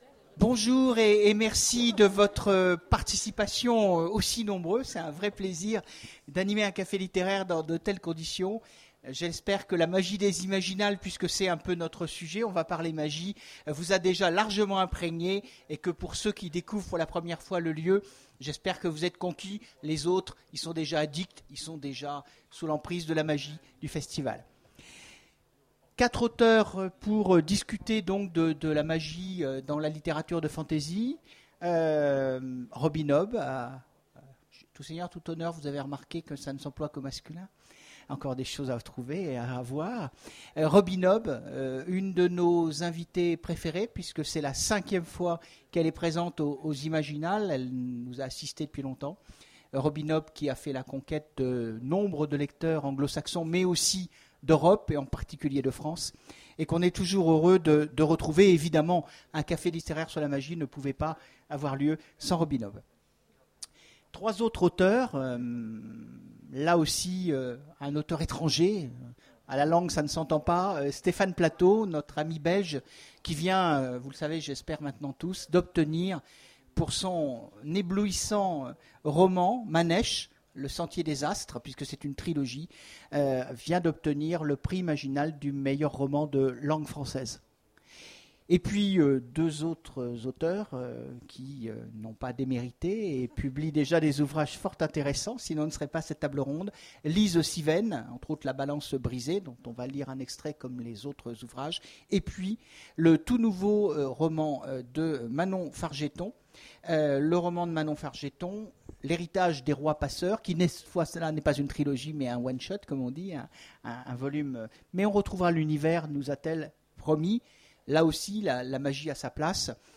Imaginales 2015 : Conférence Pratiquer la magie